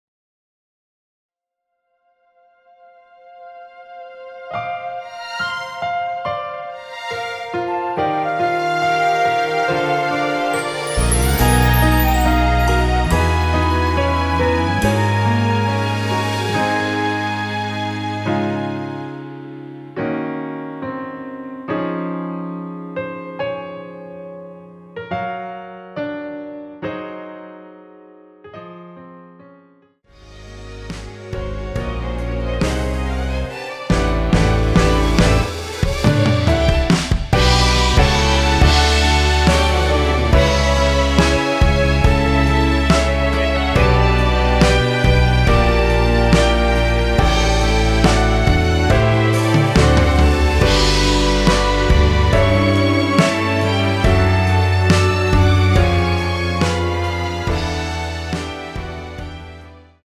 원키에서(+3)올린 MR입니다.
Db
앞부분30초, 뒷부분30초씩 편집해서 올려 드리고 있습니다.
중간에 음이 끈어지고 다시 나오는 이유는